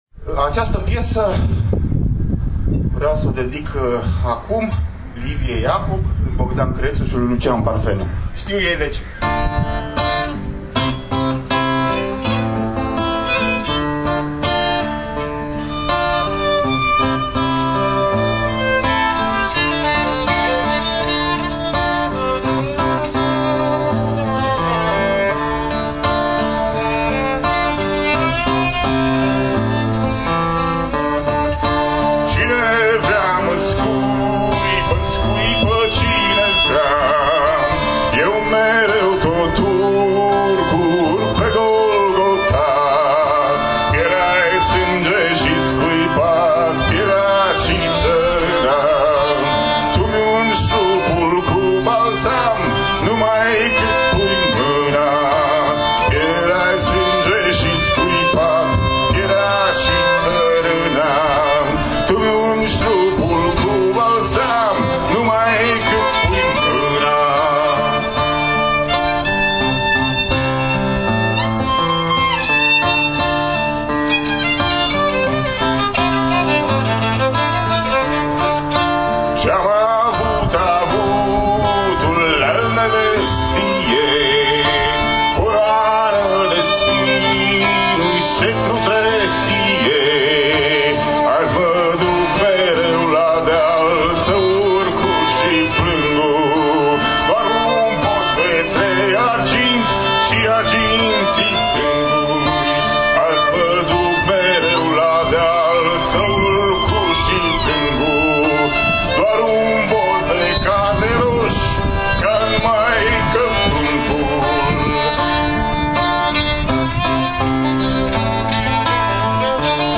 chitară și voce
vioară… interpretându-ne din Către Discipoli